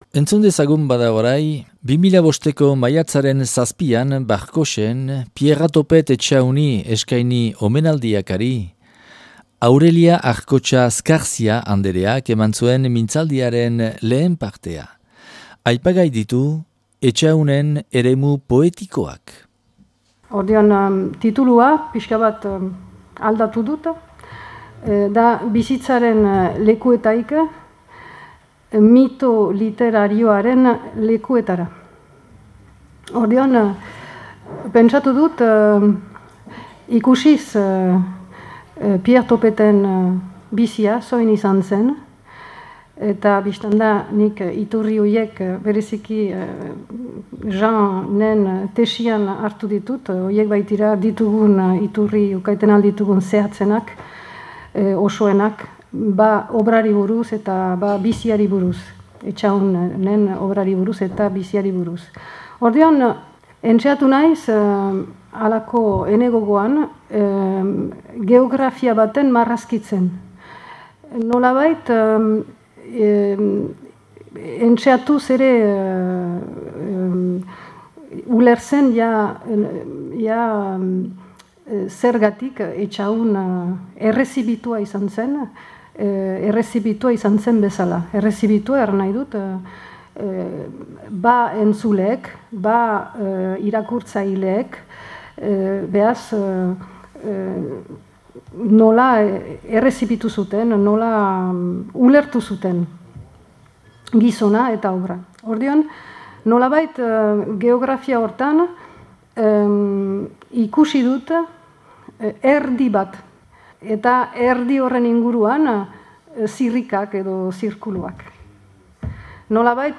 Piera Topet Etxahun omenadia Barkotxen 2005.